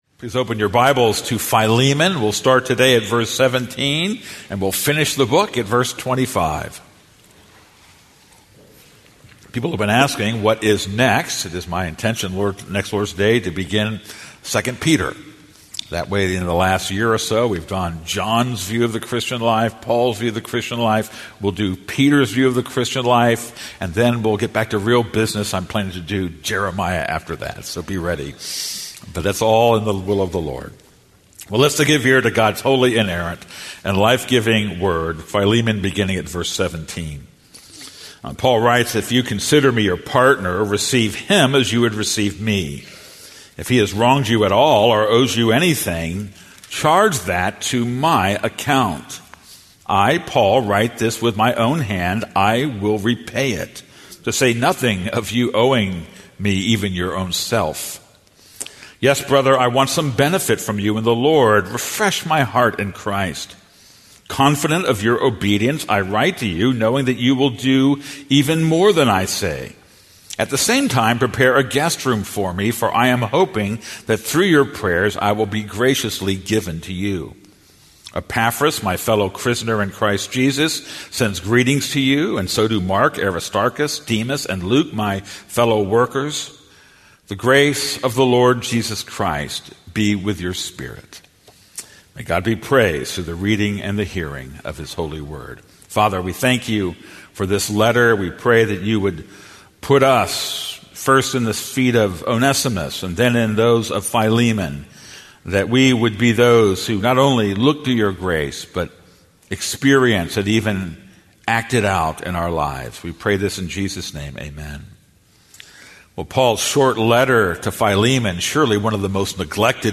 This is a sermon on Philemon 1:17-25.